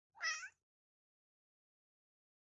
Little Cat Meow Sound Effect Download: Instant Soundboard Button